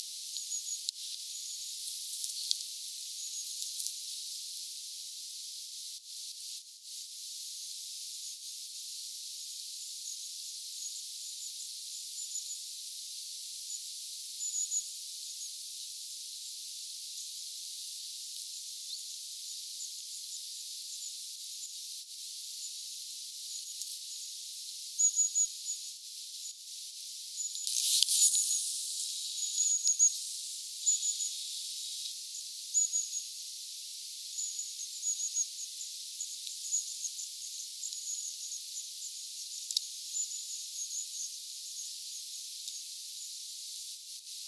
muuttava pyrstötiaisparvi, 3
muuttavia_pyrstotiaisia.mp3